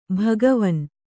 ♪ bhagavan